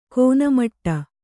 ♪ kōna maṭṭa